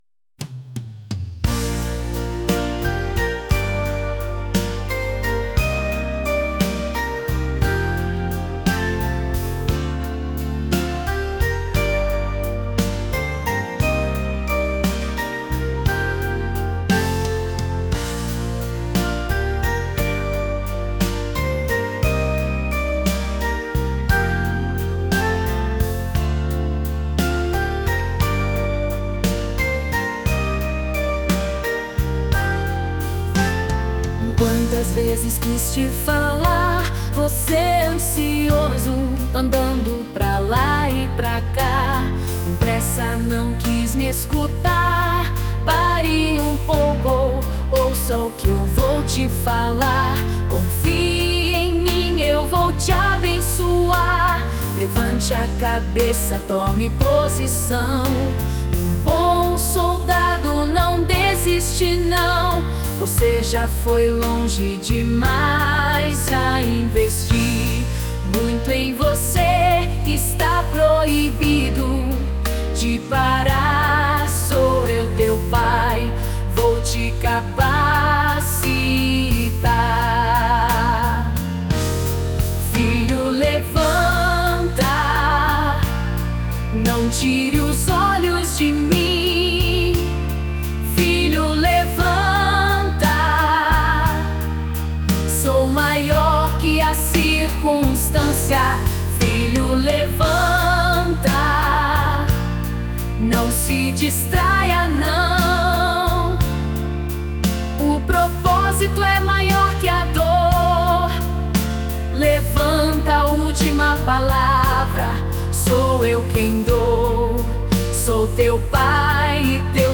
Transforme qualquer ideia em uma música incrível com voz, instrumentos de forma automática
[Vocal Feminino]